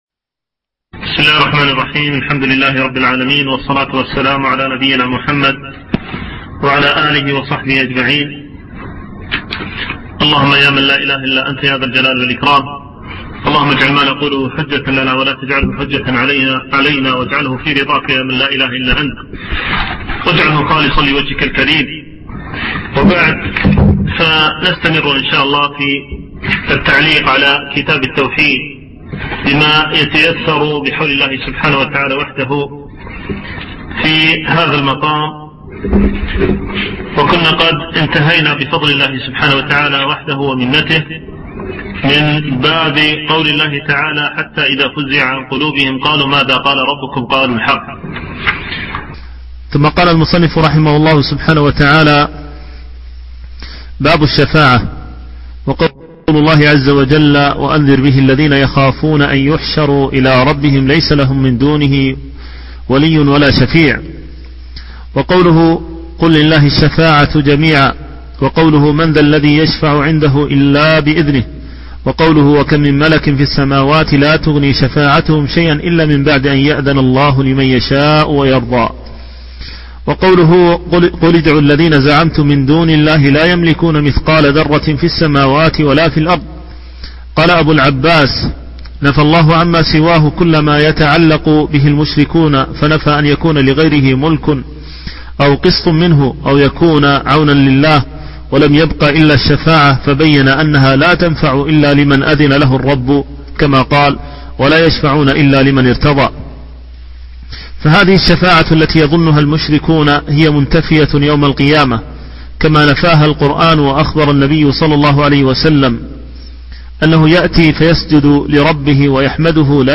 شرح كتاب التوحيد - الدرس السادس عشر